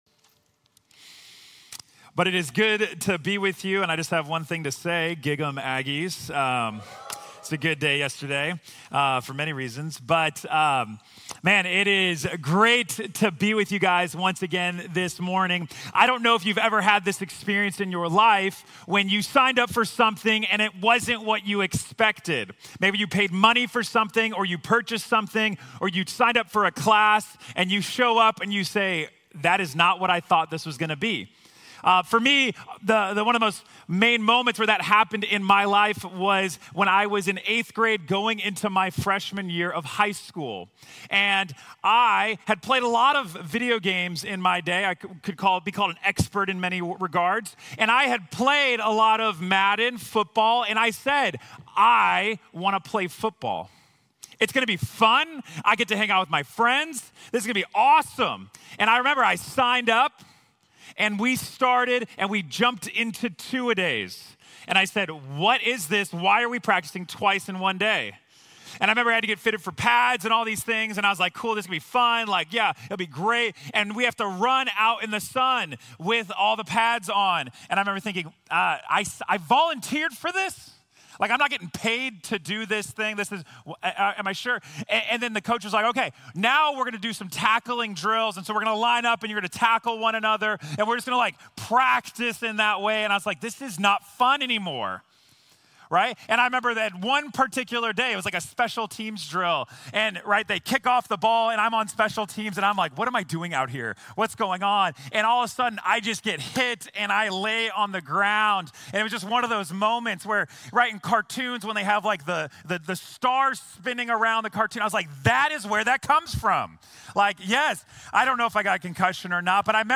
The Path of A Disciple | Sermon | Grace Bible Church